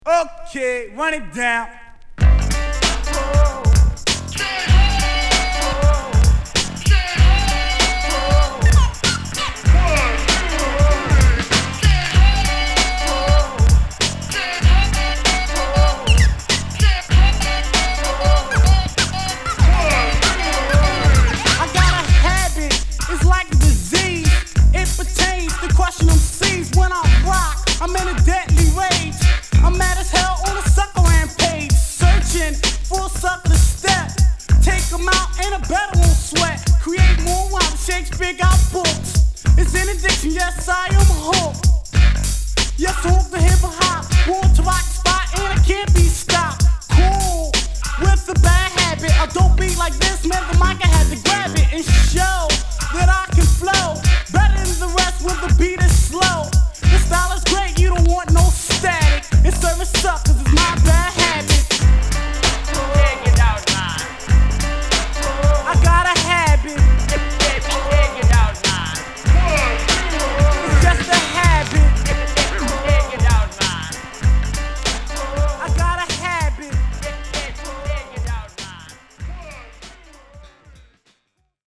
80's Philly Hip Hop Classic!!